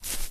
FootstepGrass06.ogg